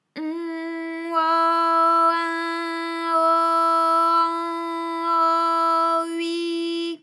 ALYS-DB-001-FRA - First, previously private, UTAU French vocal library of ALYS
on_au_in_au_an_au_ui.wav